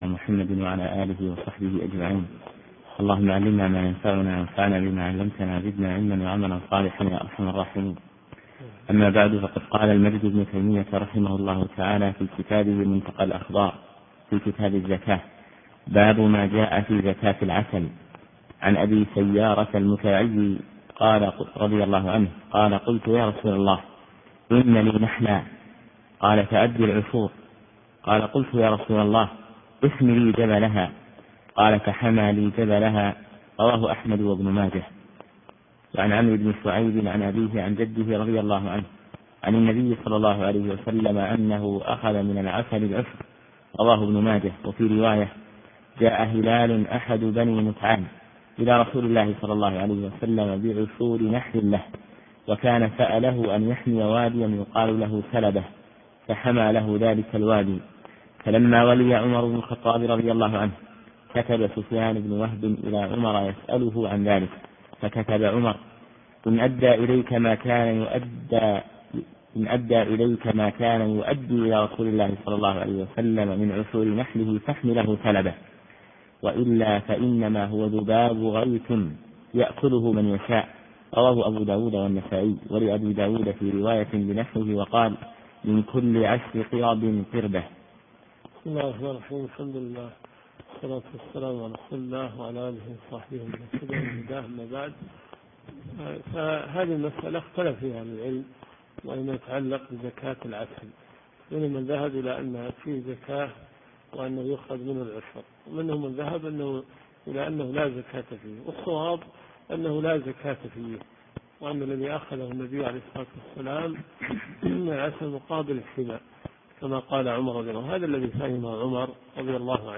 دورة صيفية في مسجد معاذ بن جبل .